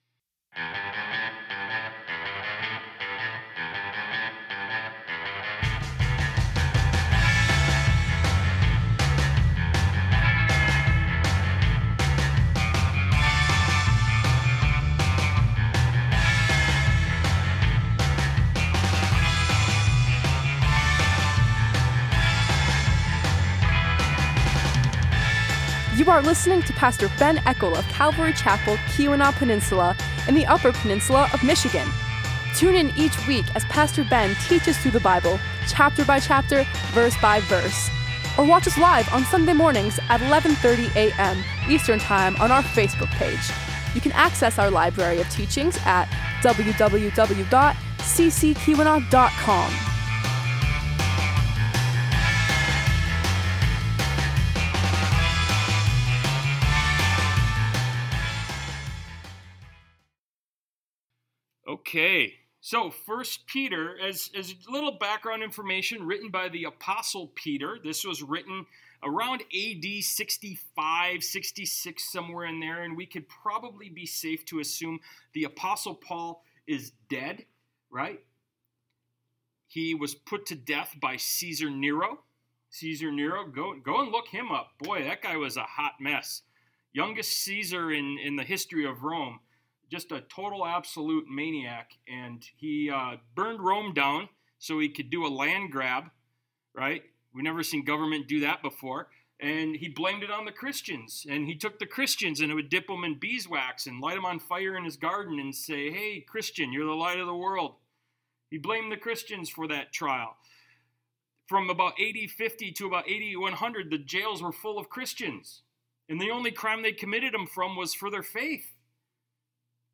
Service Type: Wednesday Night